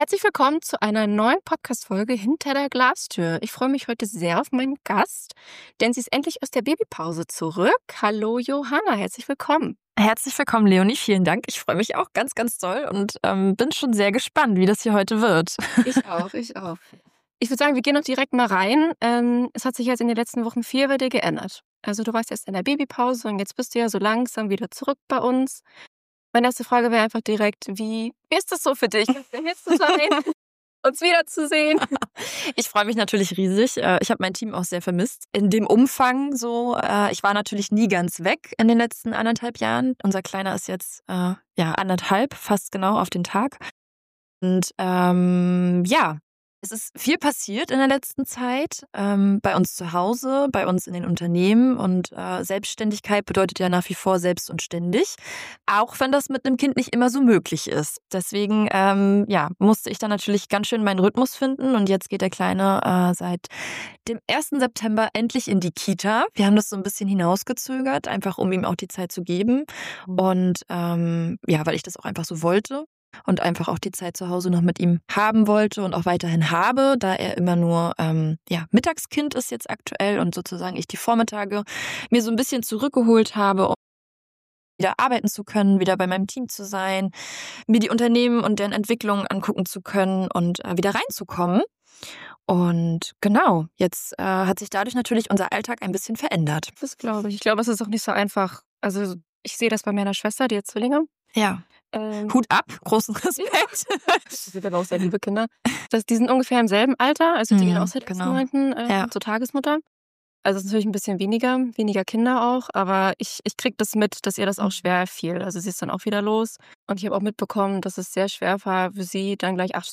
Ein Gespräch über Balance, Mut, Prioritäten – und das Leben zwischen Familie und Unternehmertum.